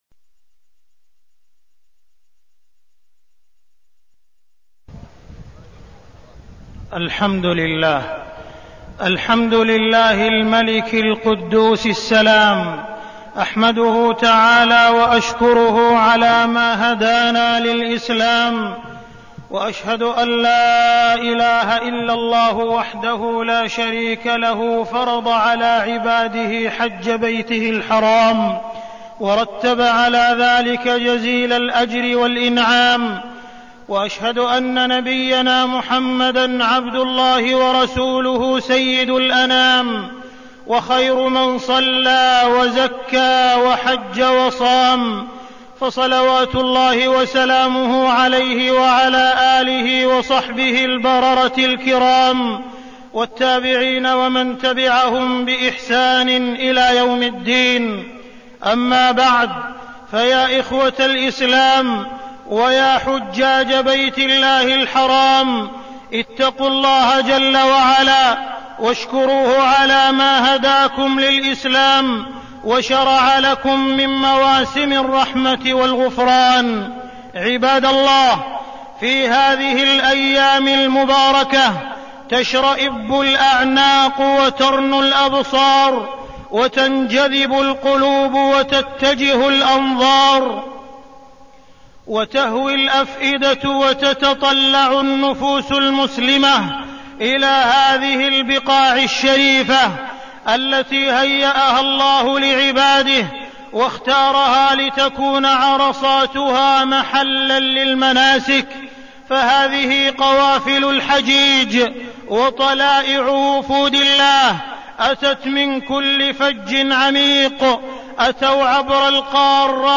تاريخ النشر ٢٢ ذو القعدة ١٤١٨ هـ المكان: المسجد الحرام الشيخ: معالي الشيخ أ.د. عبدالرحمن بن عبدالعزيز السديس معالي الشيخ أ.د. عبدالرحمن بن عبدالعزيز السديس وأذن في الناس بالحج